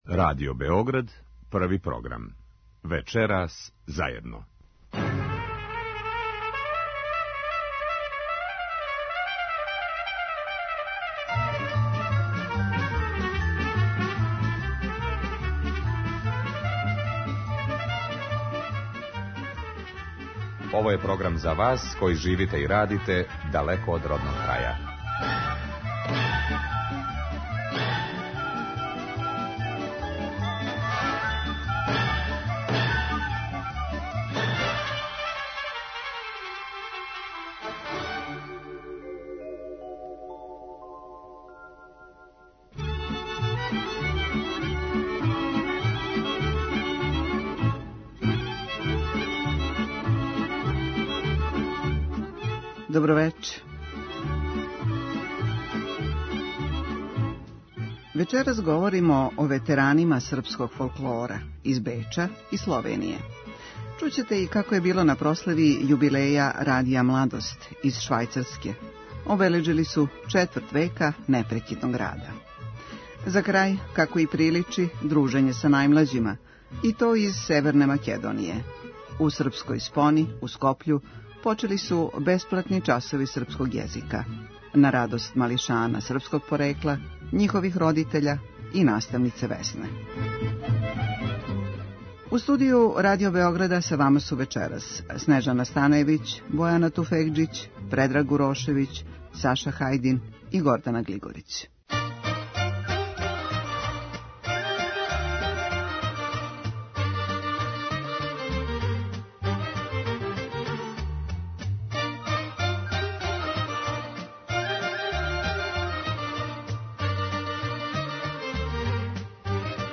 Традиционални, годишњи концерт, са учешћем свих секција, најављују за суботу, 10.децембар. У сусрет њиховом бечком концерту, емитујемо репортажу с тек одржаног „Фестивала равнице“ у Новом Саду. Секција ветерана КУД-а „Мокрањац“ наступила је, међу двадесет друштава, као једини гост из дијаспоре.